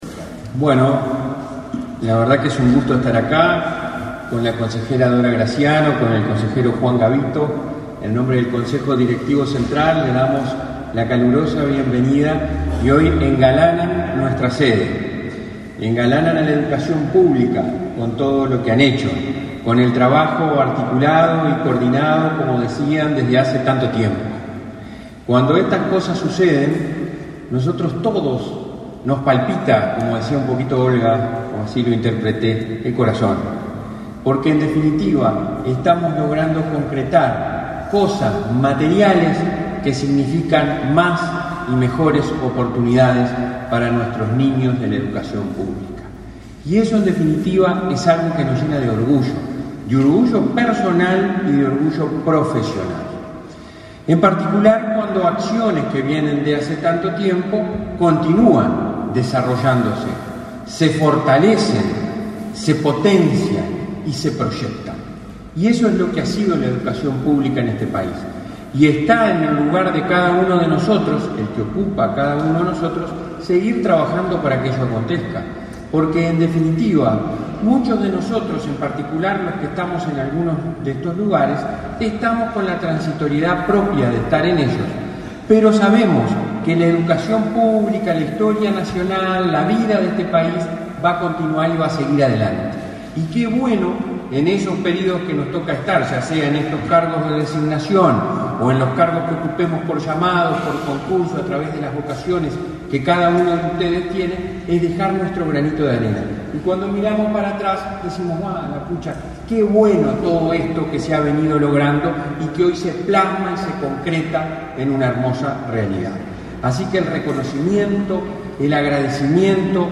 Conferencia de prensa por presentación de serie de libros realizados por ANEP